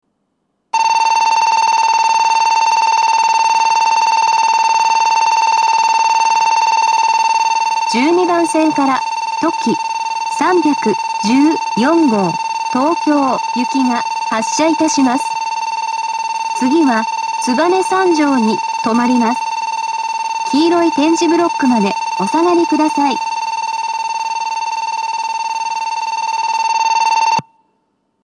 ２面４線のホームで、全ホームで同じ発車ベルが流れます。
２０２１年９月１２日にはCOSMOS連動の放送が更新され、HOYA製の合成音声による放送になっています。
１２番線発車ベル とき３１４号東京行の放送です。